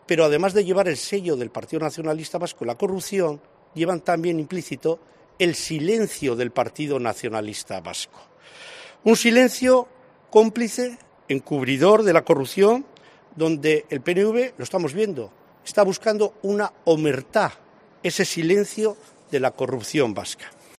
Iturgaiz ha realizado estas declaraciones en un acto celebrado en Alonsotegi, ante un mapa de la Comunidad Autónoma Vasca en el que han expuesto los supuestos casos de corrupción del PNV.